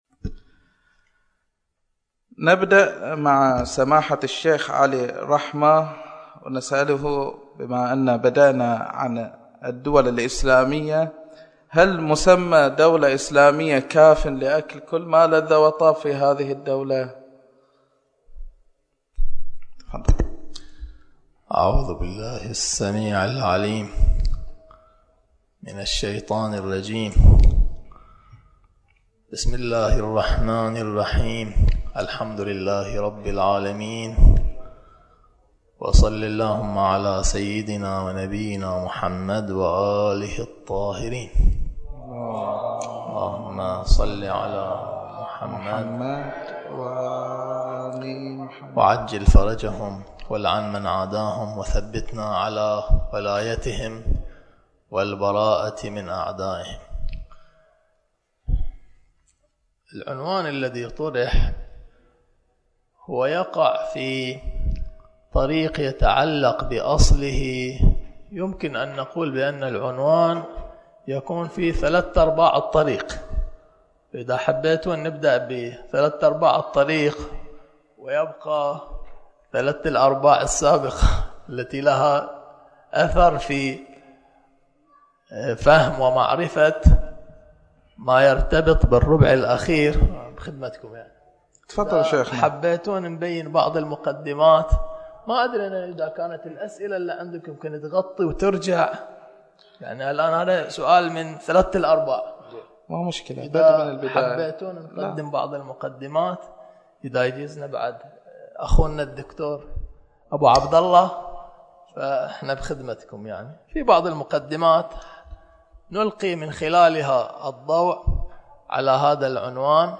ضمن برنامج جمعة أهل الذي ينظمه مأتم النعيم الغربي من كل شهر و يستضيف فيه نخبة من المحاضرين و الكتاب و الأساتذة ، أقيم مساء أمس الجمعة في الساعة 8:00 الموافق 3 / 1 / 2014م موضوع " اللحوم في الأسواق بين الحلال و الحرام " .